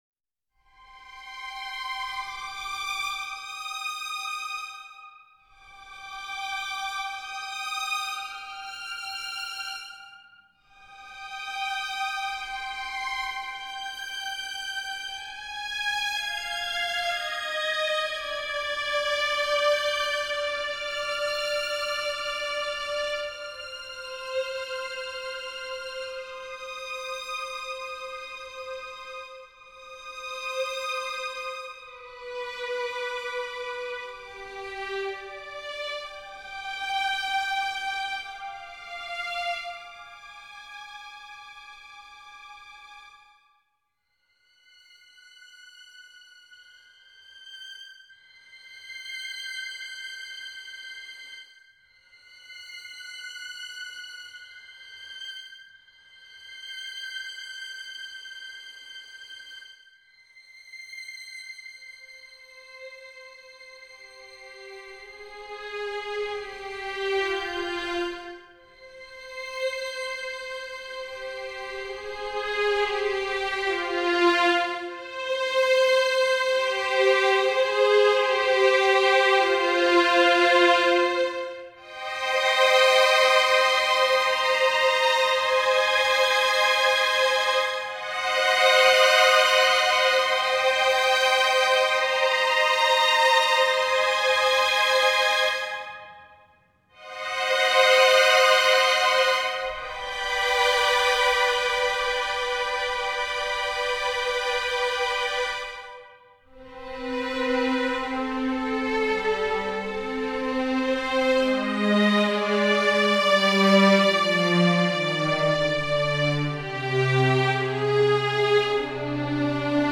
string orchestra
trumpets
transverse flutes
percussion
clarinet
orchestral work in 7 pieces (21:15)